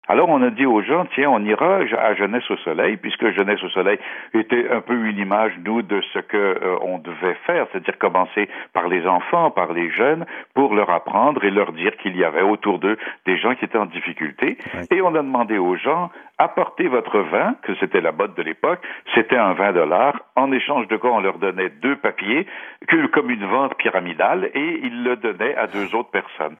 El llamado que él hizo esa mañana fue completamente espontáneo, dice Joël Le Bigot en entrevista con Alain Gravel esta mañana, que es el presentador del programa radial matinal de Radio Canadá en francés, que ahora se llama Gravel le matin (Gravel por la mañana), que está en ondas desde la 5:30 hasta las 9:00 de la mañana.